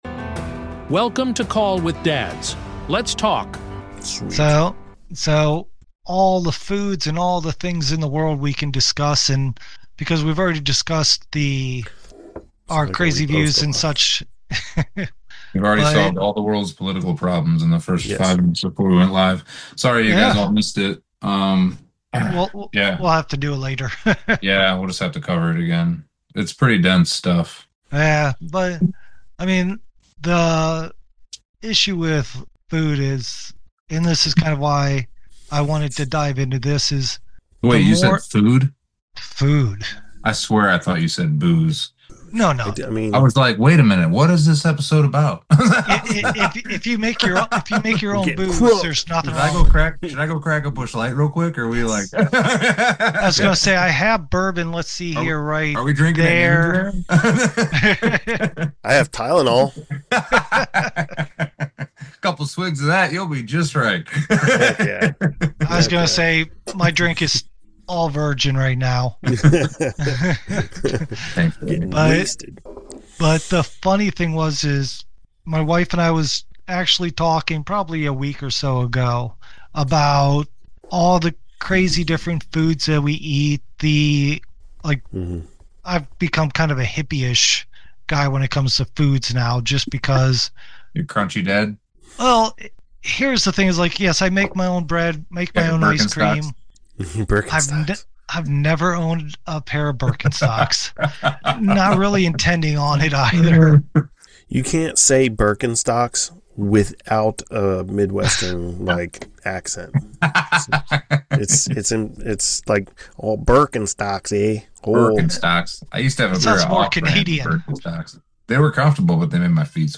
Mixed with plenty of laughs about “crunchy dad” stereotypes, bug farms, and off-topic tangents, it’s a wake-up call wrapped in real talk.